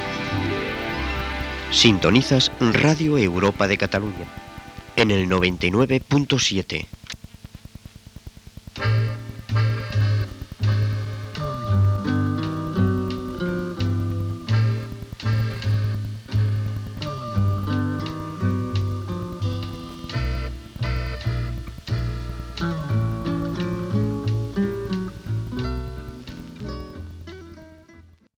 d2044dba5a5ec19c076e08fa38be211a1b1e92f5.mp3 Títol Radio Europa de Cataluña Emissora Radio Europa de Cataluña Titularitat Tercer sector Tercer sector Comercial Descripció Identificació i música.